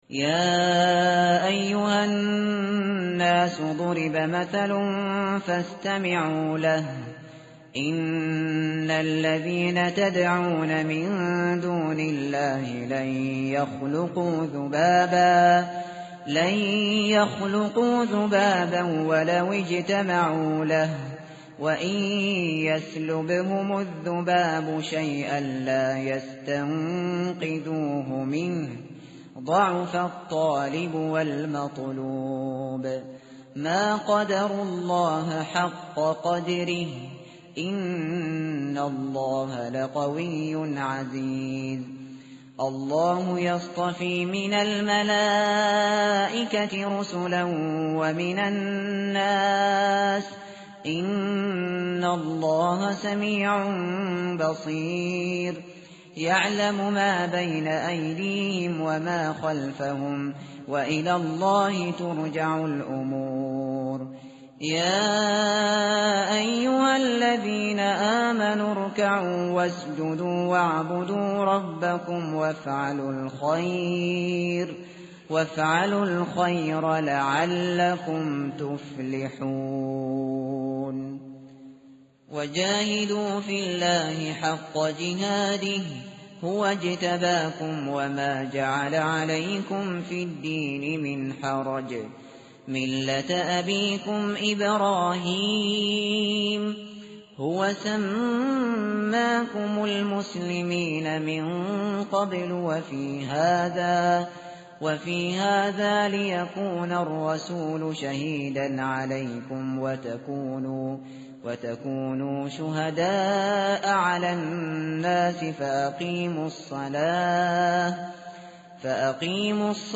متن قرآن همراه باتلاوت قرآن و ترجمه
tartil_shateri_page_341.mp3